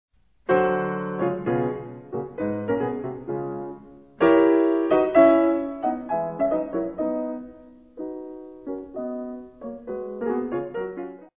Beispiel: Alteration
So wie Beethoven seine Sonate op. 31 Nr. 3 ganz unkonventionell mit einer "Sixte ajoutée" beginnen lässt (siehe Beispiel), so eröffnet ein - ohne den Kadenzzusammenhang sehr kühner - Dominantseptakkord mit tiefalterierter Quint den Schlusssatz der Fis-Dur-Sonate (doppeldominantisch, also in Gis-Dur und ohne Grundton, also verkürzt).